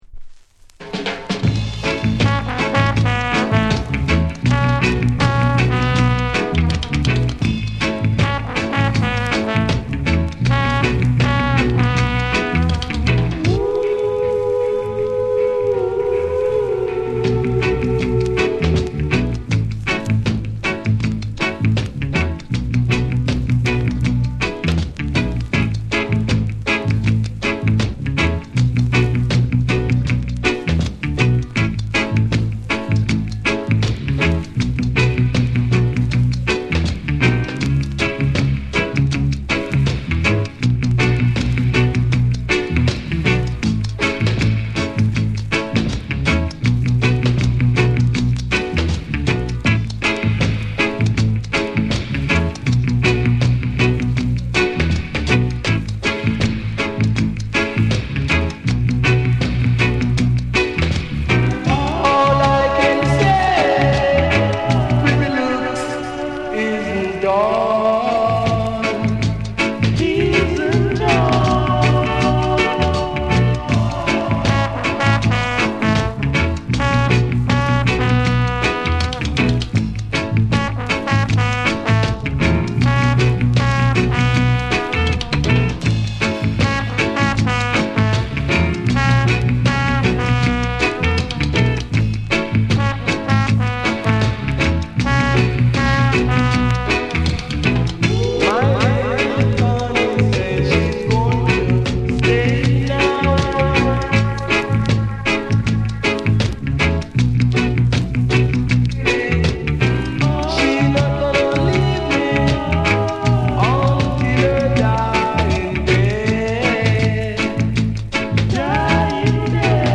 温かみのあるコーラスワークの余韻を残しつつ、エコーやリバーブが深く空間に広がる王道ルーツ・ダブを展開。
じっくりと浸れるクラシックなダブ作品。
REGGAE & DUB